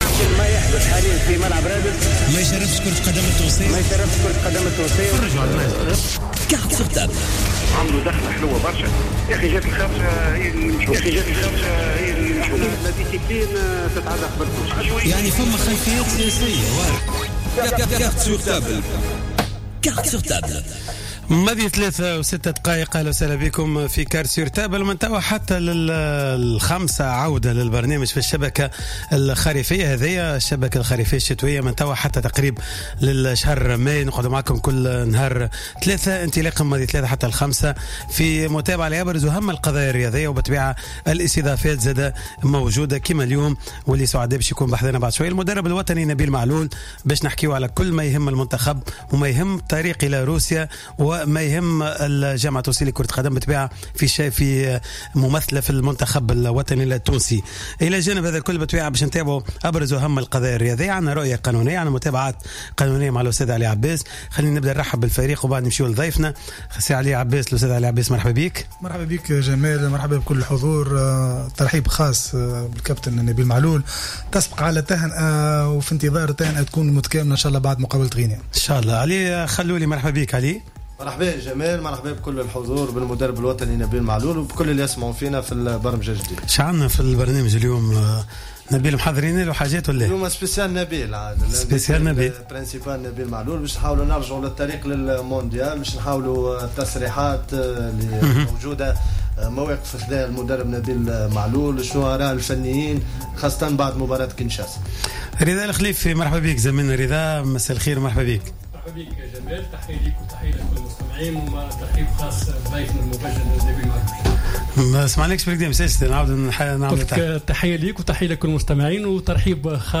حل المدرب الوطني نبيل معلول ضيفا على حصة "cartes sur table" للحديث عن العديد من الأمور التي تخص التحديات القادمة أمام المنتخب على درب التأهل إلى مونديال روسيا 2018 .